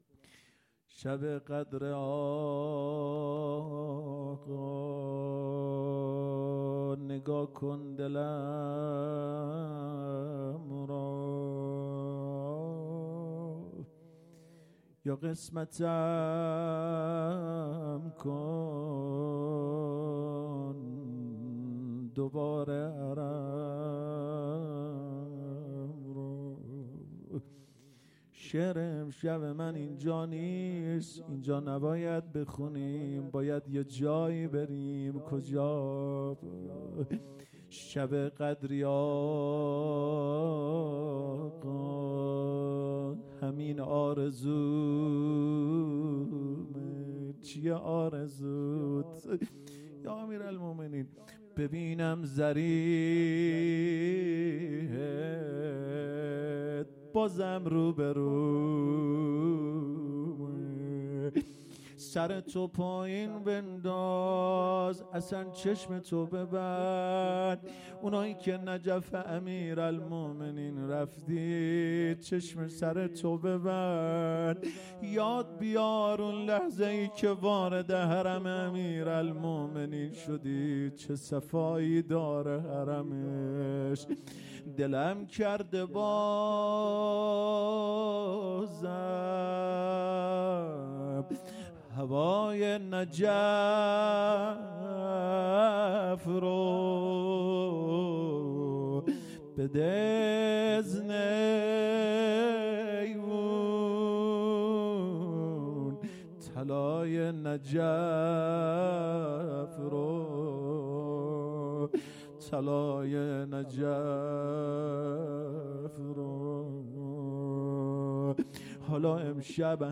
هیئت مسجد صاحب الزمان عج
شب اول قدر مناجات